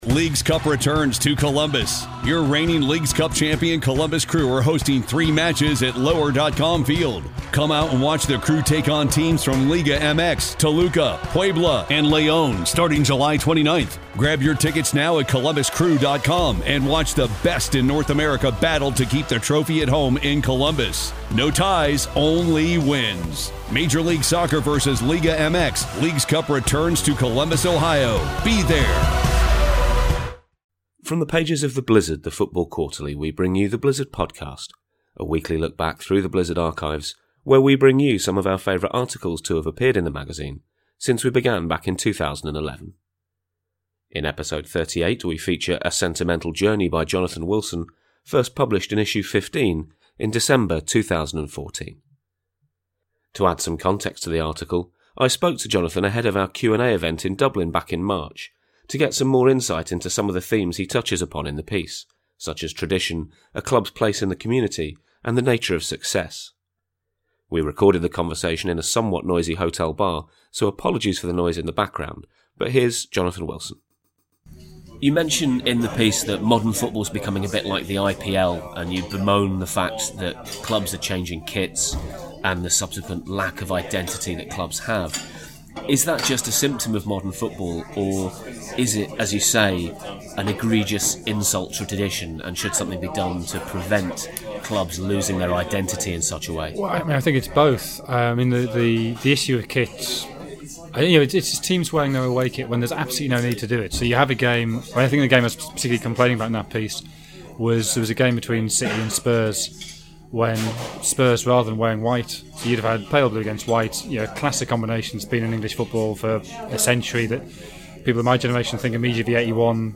In Episode Thirty Eight we look back to ‘A Sentimental Journey’ by Jonathan Wilson, including a chat with the man himself to put the article in a bit more context. In a world of superclubs, just what is the point of ordinary teams?